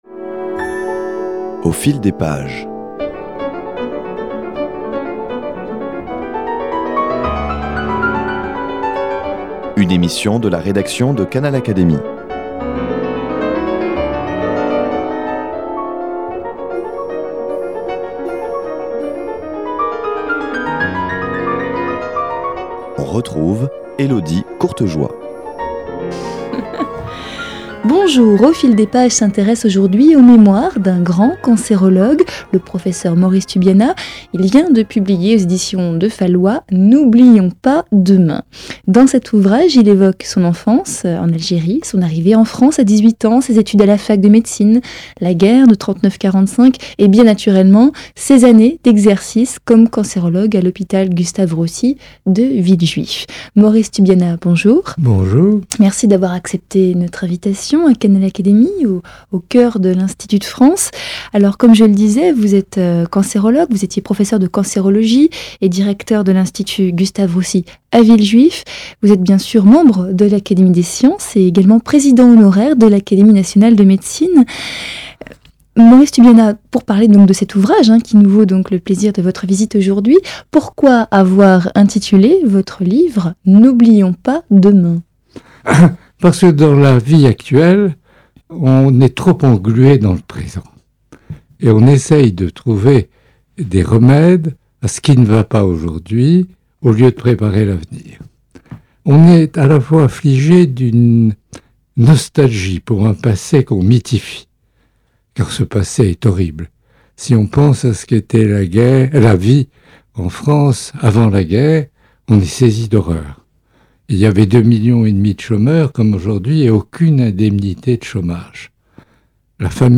Rencontre avec l’auteur Maurice Tubiana.